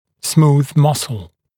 [smuːð ‘mʌsl][сму:з ‘масл]гладкая мышца